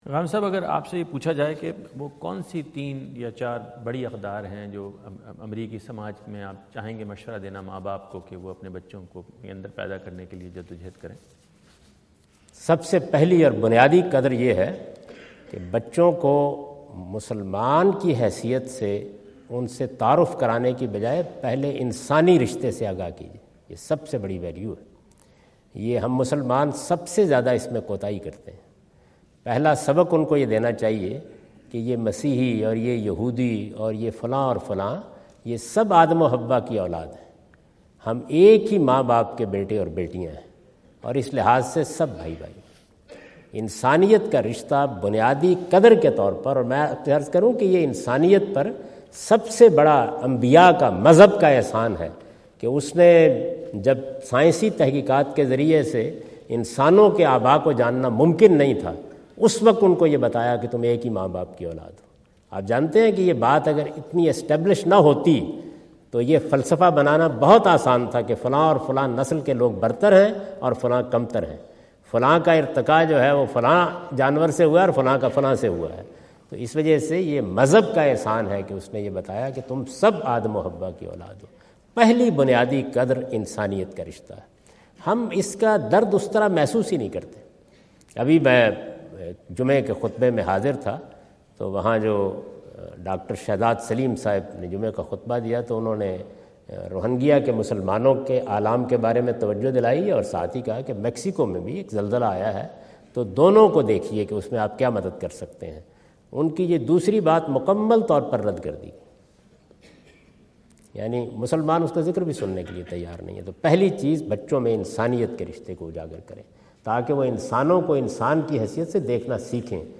Javed Ahmad Ghamidi answer the question about "Teaching Basic Values to Children" asked at North Brunswick High School, New Jersey on September 29,2017.